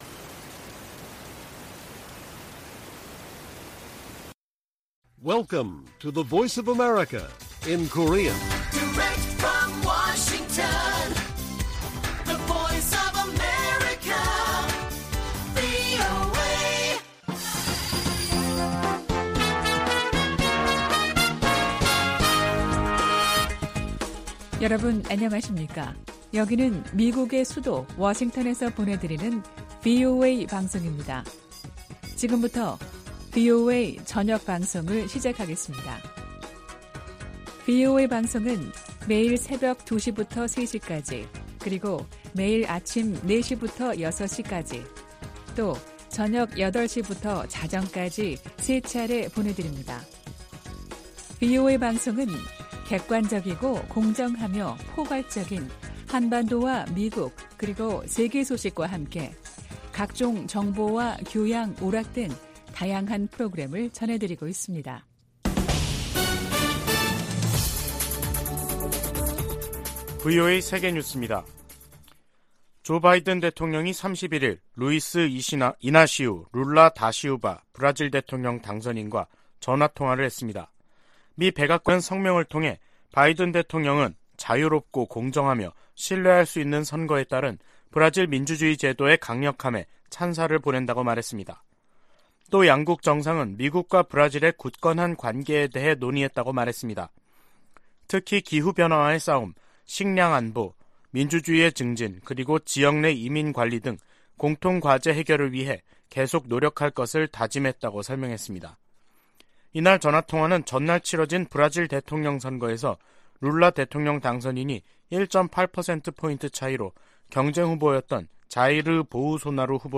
VOA 한국어 간판 뉴스 프로그램 '뉴스 투데이', 2022년 11월 1일 1부 방송입니다.